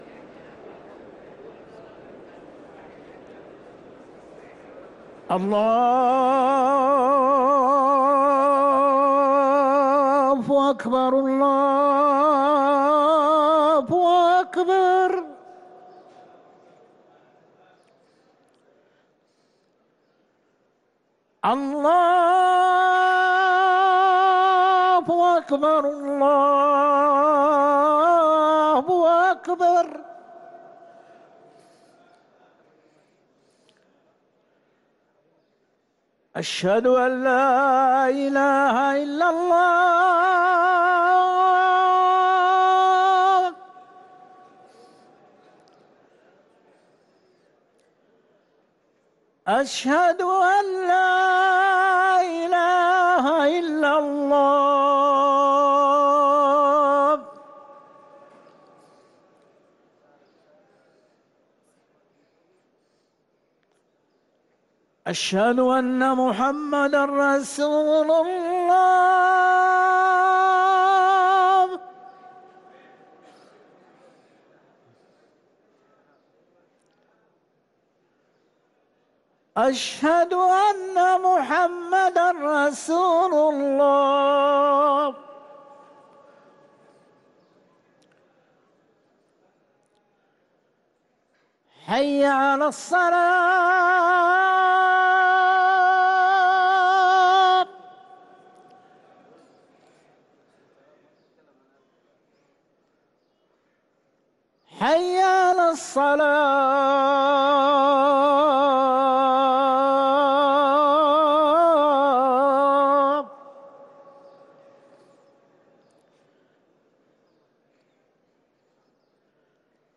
أذان العصر